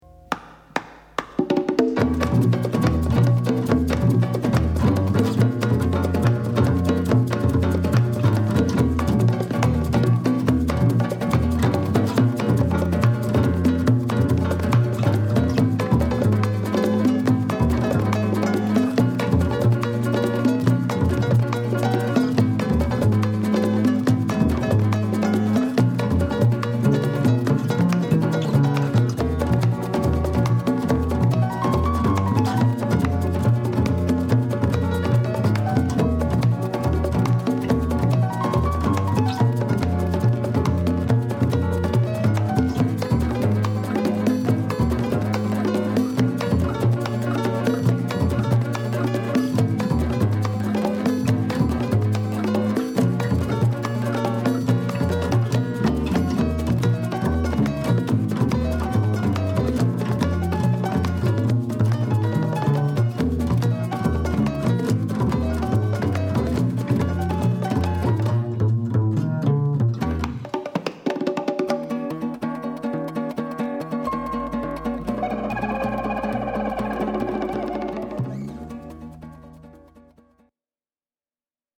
中南米サルサ／ラテンバンド
クラブ・ストリート等で活躍するジャパニーズ中南米音楽バンド
エモーショナルなピアノとパーカッションが展開する情熱的なタイトル曲
サウダージ感漂うオーガニックな全4曲入りシングルです。